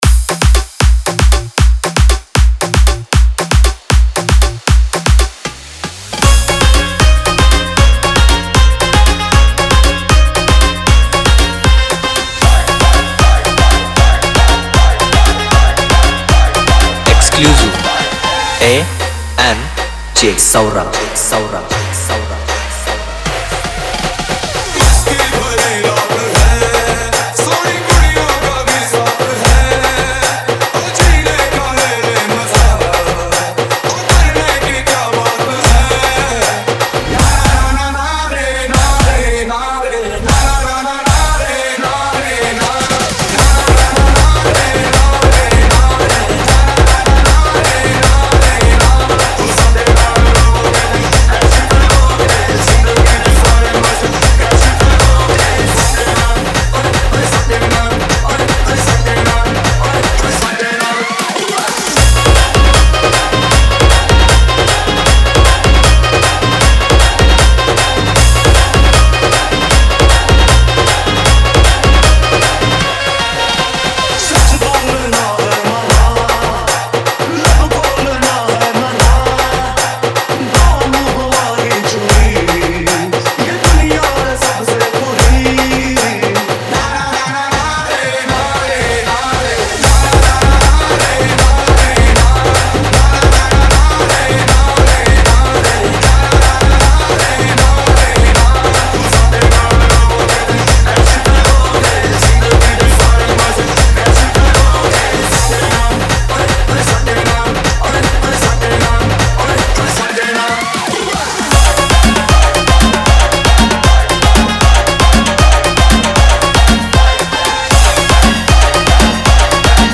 Marathi Dj Single 2025